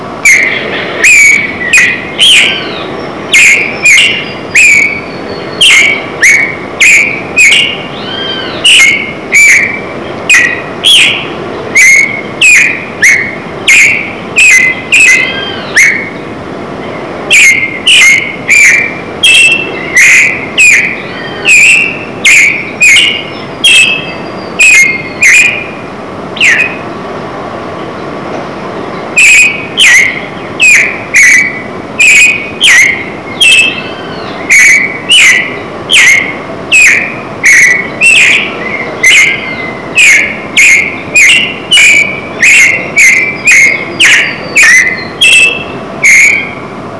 Turdus amaurochalinus - Sabiá común
sabia.wav